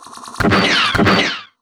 cora_guardtower_attack.wav